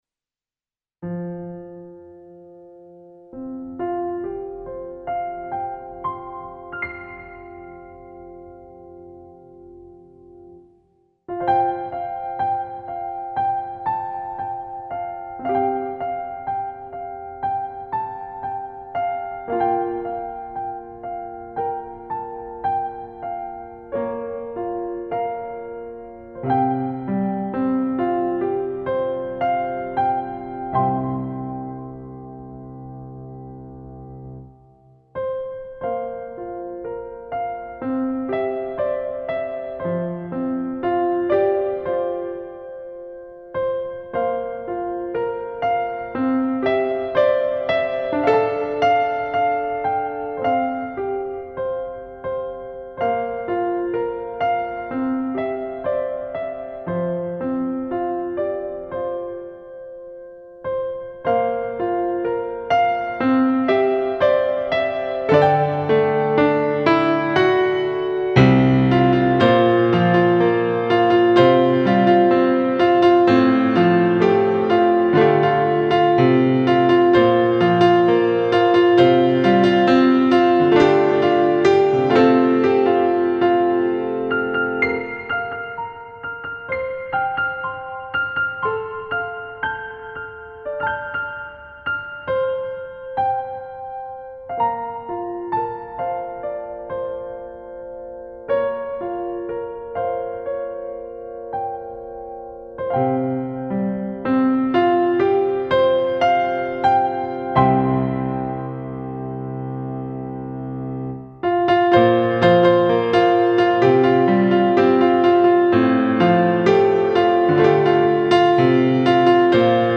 ピアノアレンジバージョン。
恋愛ドラマの感動シーンに流れるようなサウンドです。